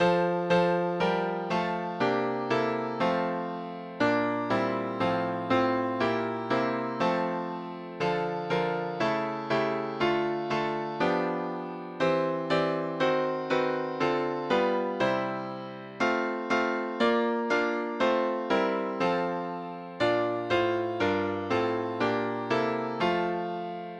Juletræet med sin pynt Mogens Lorentzen Egil Harder S A T B